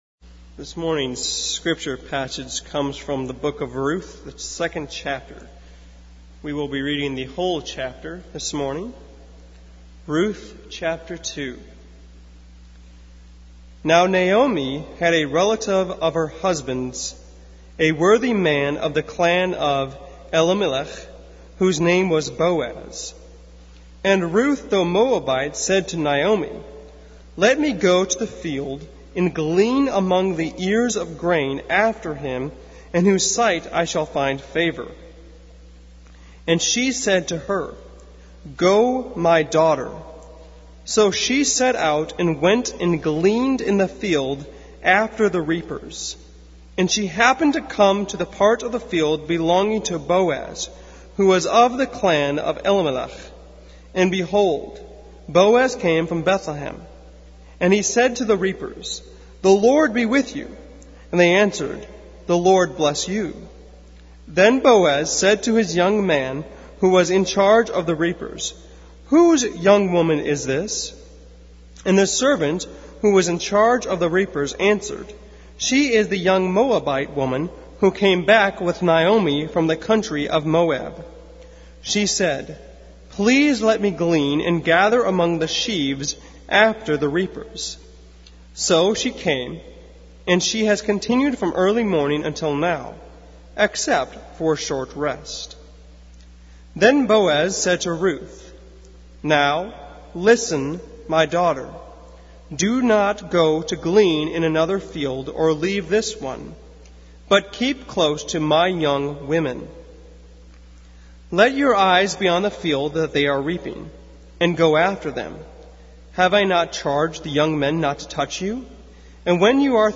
Passage: Ruth 2:1-23 Service Type: Sunday Morning